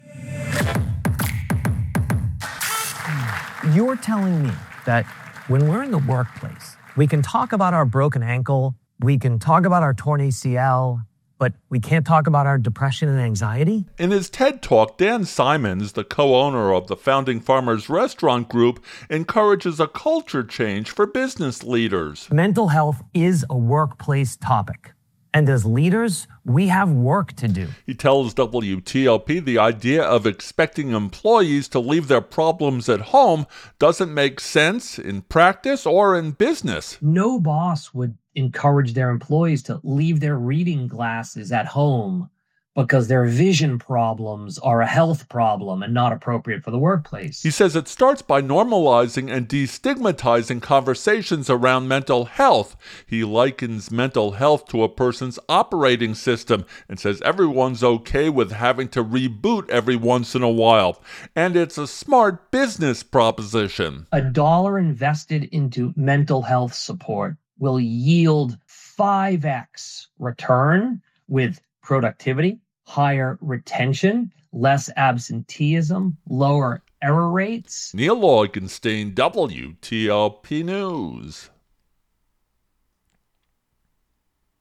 In a WTOP interview